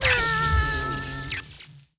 Npc Catzap Sound Effect
npc-catzap-4.mp3